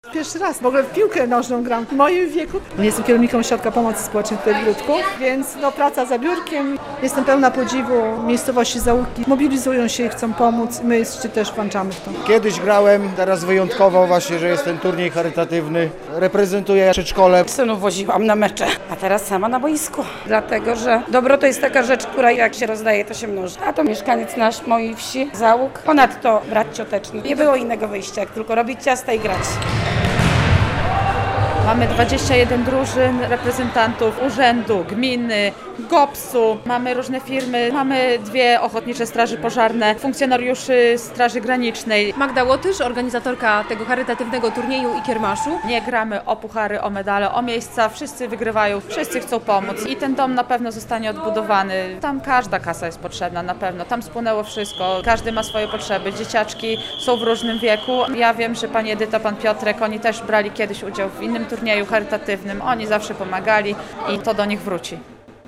Mieszkańcy gminy Gródek zbierają pieniądze na pomoc rodzinie z Załuk - relacja